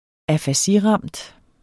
Udtale [ afaˈsi- ]